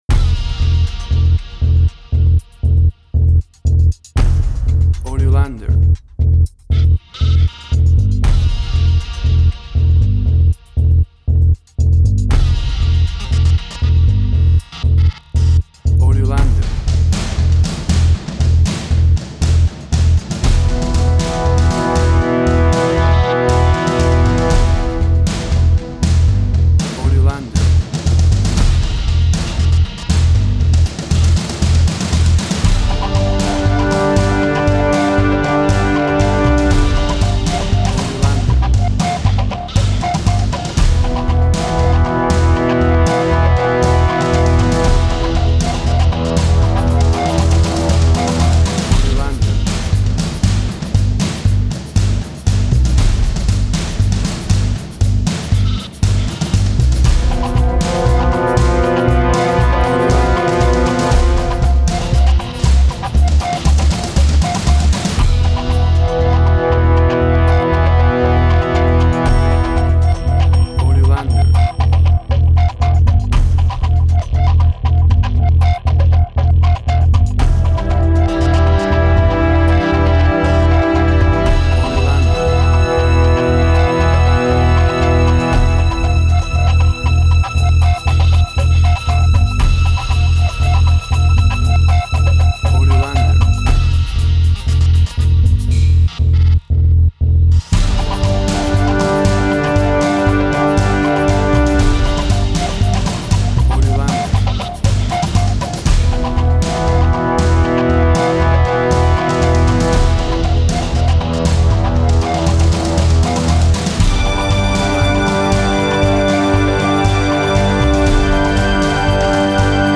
Post-Electronic.
Tempo (BPM): 118